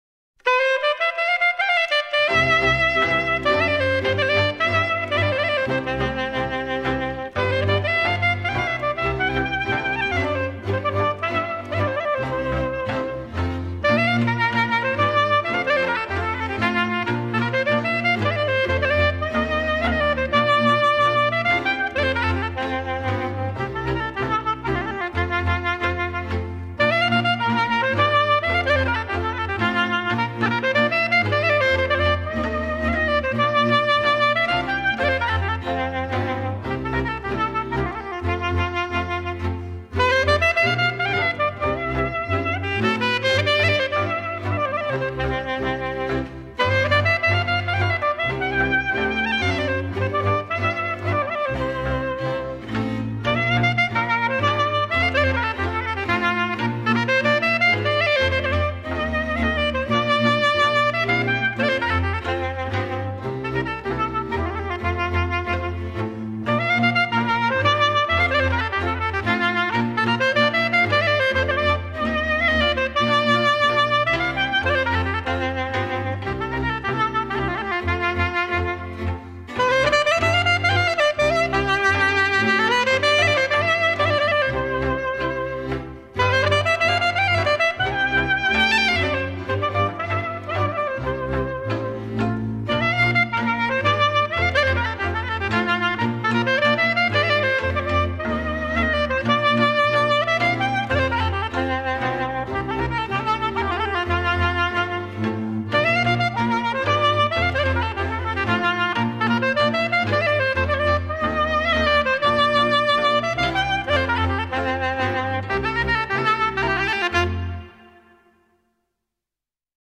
cu acompaniamentul orchestrei